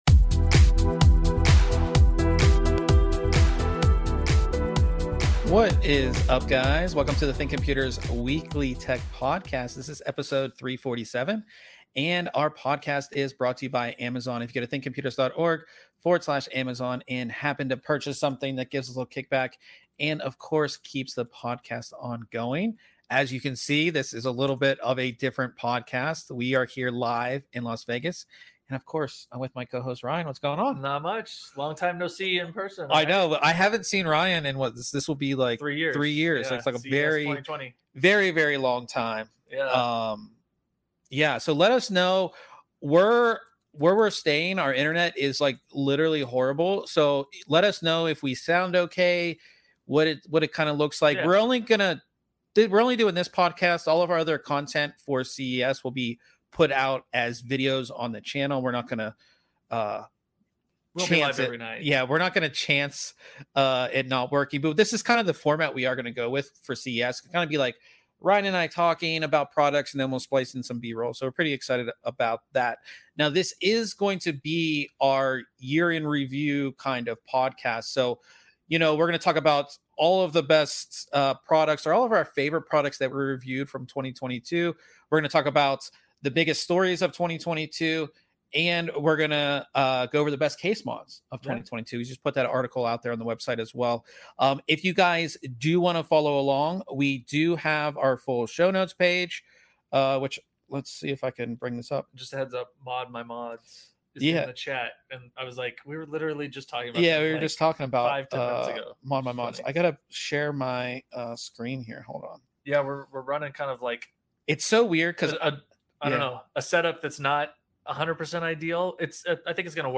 This week on the podcast we do our yearly recap going over our favorite products, the biggest news stories, and our favorite case mods of the year! We are also recording LIVE from Las Vegas as we are prepping for CES 2023!